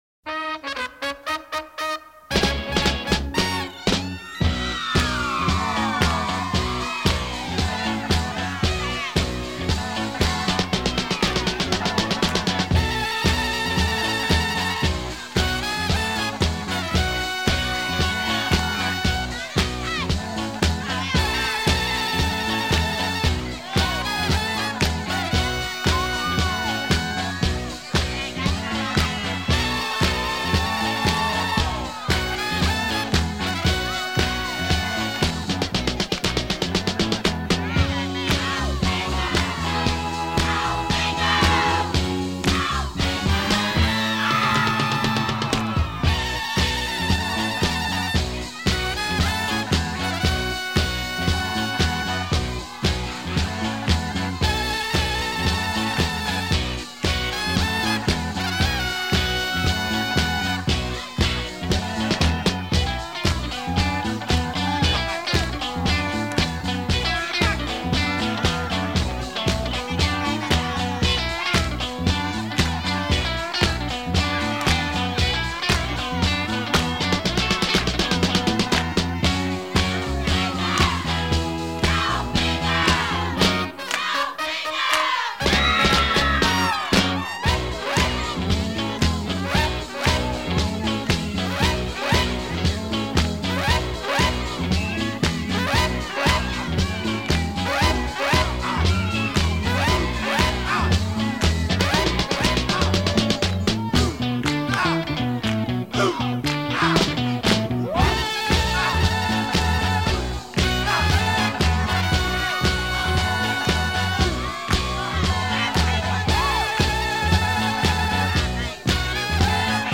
Tempo : 115